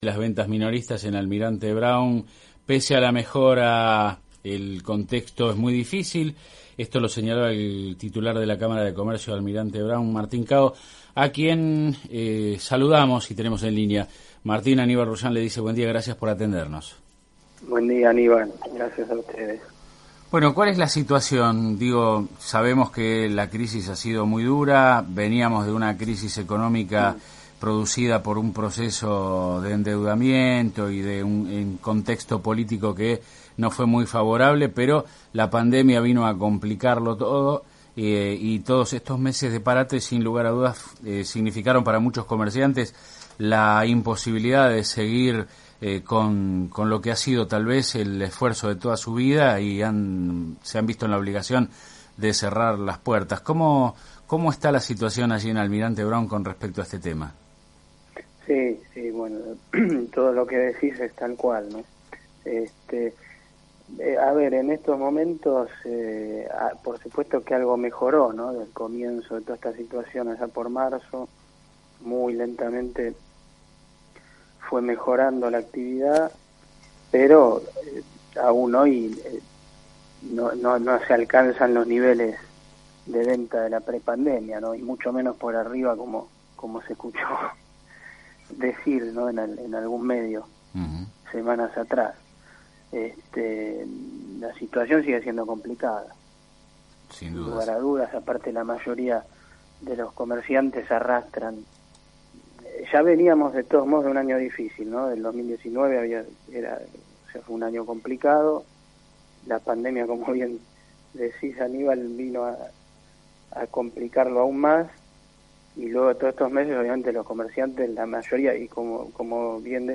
Actualidad del Comercio en Almirante Brown – Entrevista